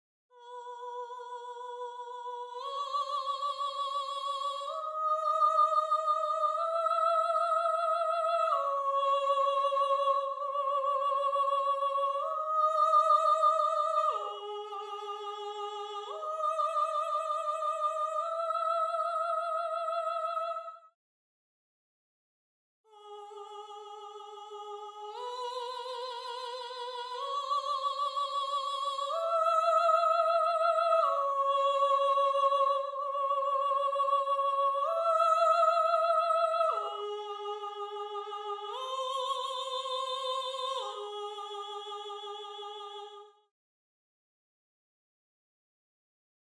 2. Voice 2 (Soprano/Soprano)
gallon-v8sp1-20-Soprano_1.mp3